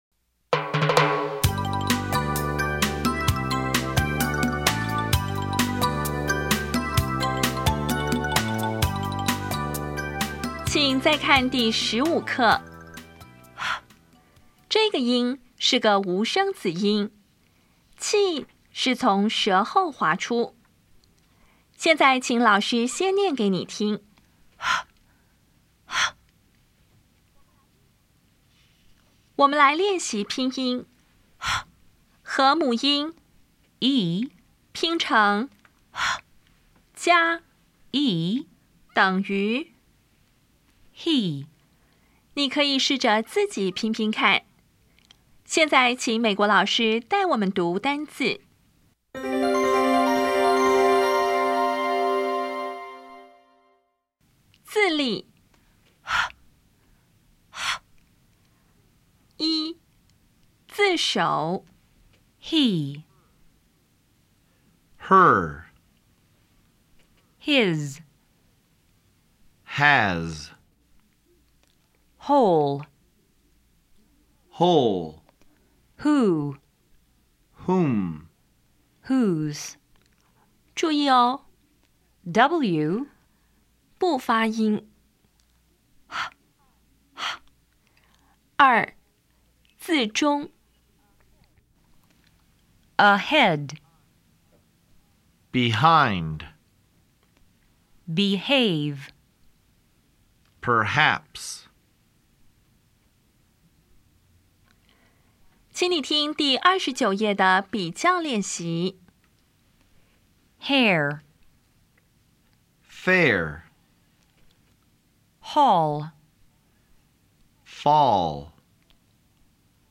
当前位置：Home 英语教材 KK 音标发音 子音部分-1: 无声子音 [h]
音标讲解第十五课
[əˋhɛd]
比较[h][f]      [h](无声) [f](无声)